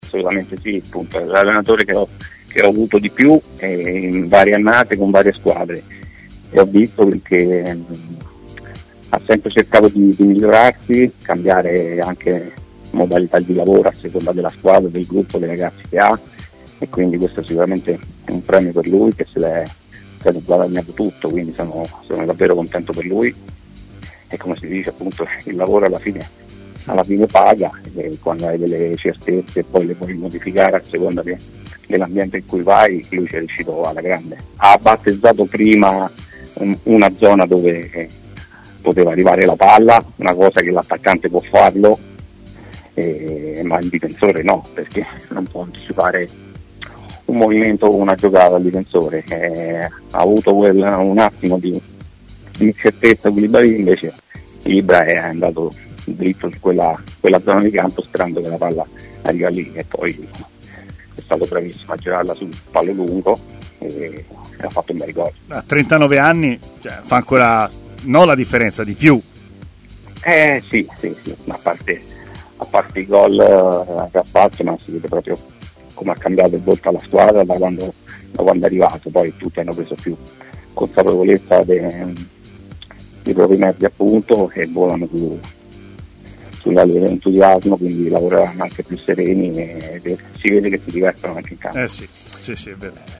Davide Moscardelli, ex attaccante e neo componente dello staff tecnico del Pisa, è intervenuto ai microfoni di Tmw Radio